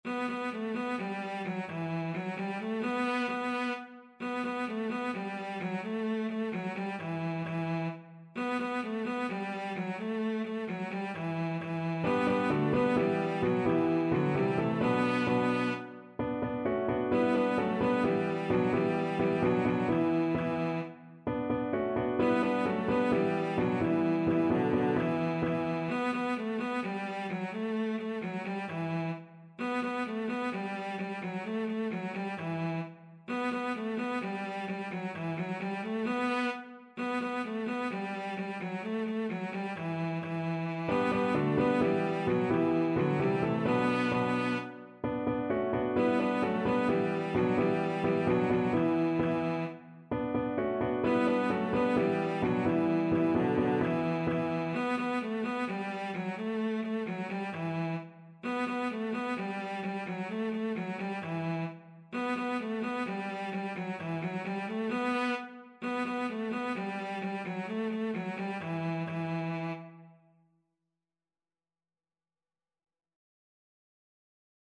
Classical
2/2 (View more 2/2 Music)
Cello  (View more Easy Cello Music)